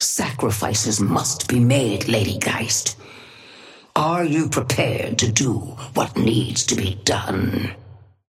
Sapphire Flame voice line - Sacrifices must be made, Lady Geist.
Patron_female_ally_ghost_oathkeeper_5g_start_01.mp3